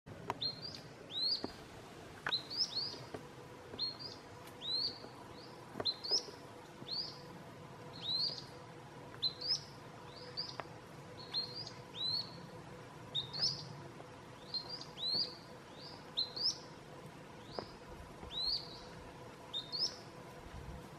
Grey-bellied Spinetail (Synallaxis cinerascens)
Life Stage: Adult
Location or protected area: Floresta Nacional de São Francisco de Paula
Condition: Wild
Certainty: Observed, Recorded vocal
Synallaxis-cinerascens.mp3